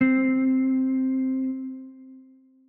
Bell - Islands.wav